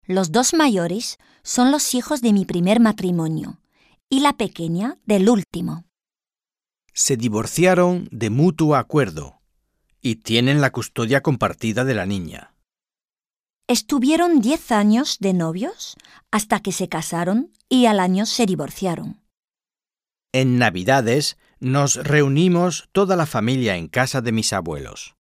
Un peu de conversation - La famille recomposée